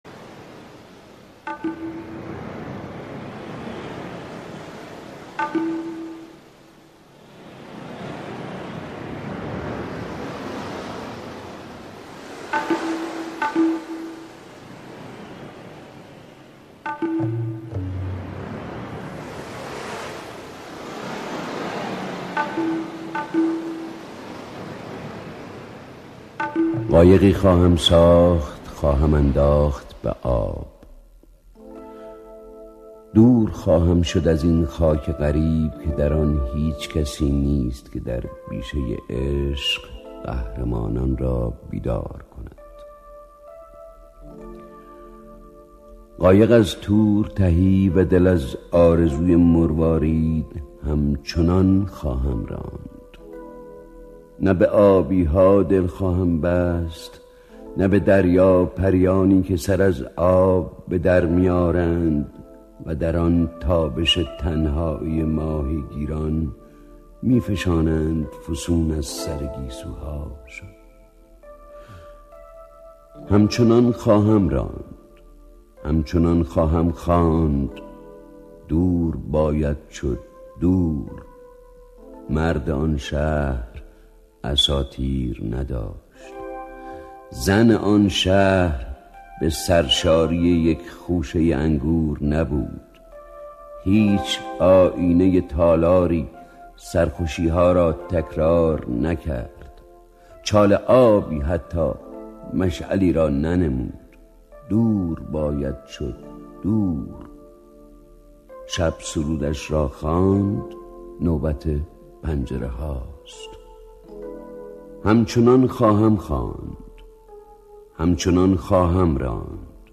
قایقی خواهم ساخت شعر زیبای سهراب سپهری با صدای دل نشین مرحوم خسرو شکیبایی